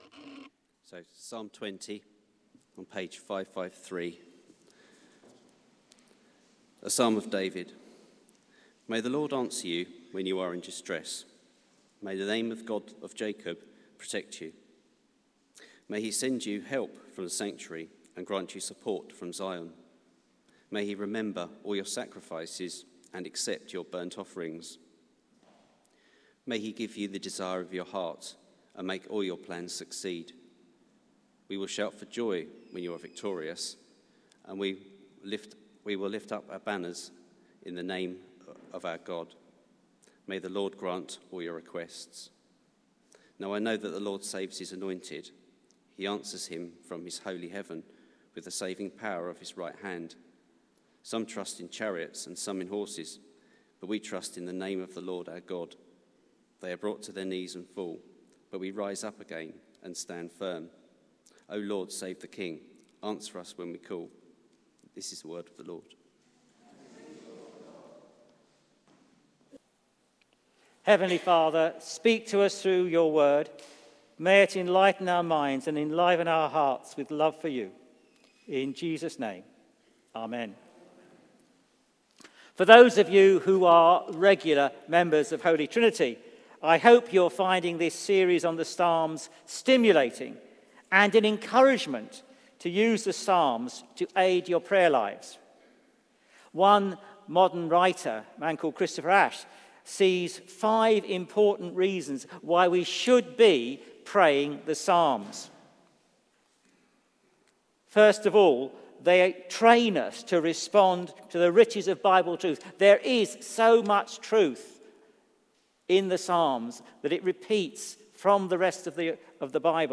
Psalms Book One: The Prayers of God's King Theme: The Victorious King Sermon